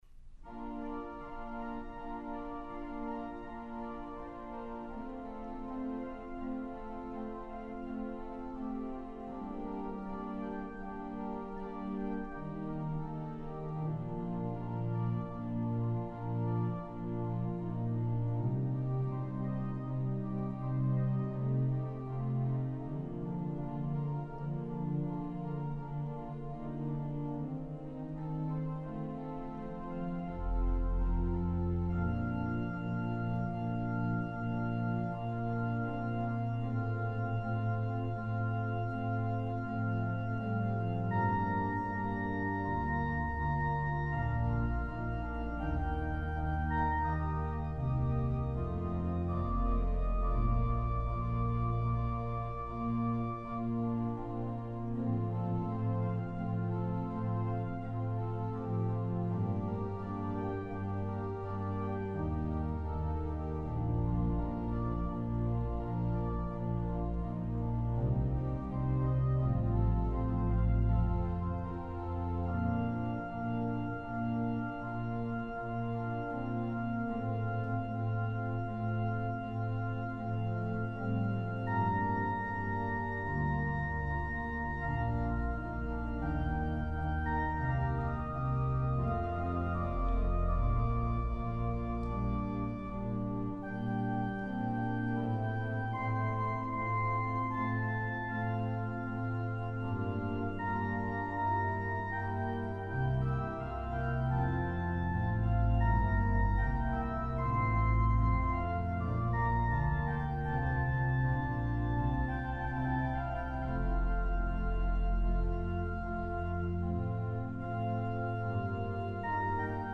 Voicing: Organ Solo